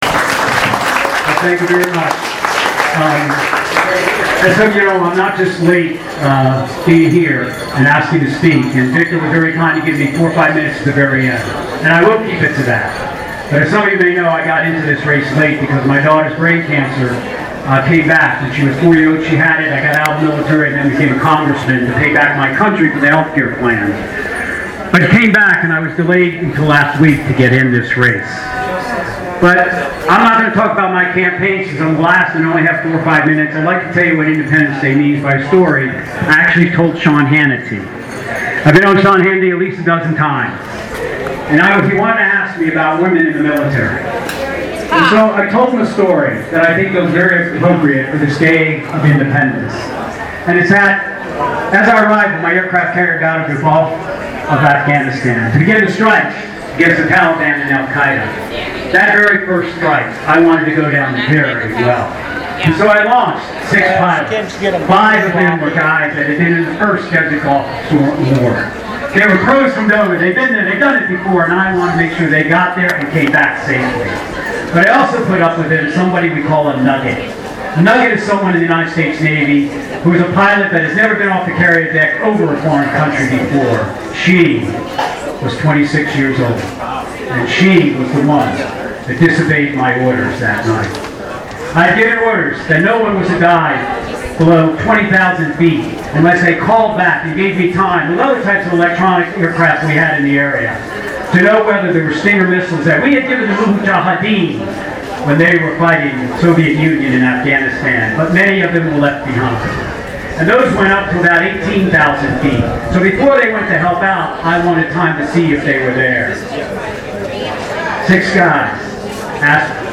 Retired Navy Admiral Joe Sestak, the latest Democrat to enter the presidential race, shared his thoughts about the president’s orders during a short speech at the West Des Moines Democrats picnic on July 3 (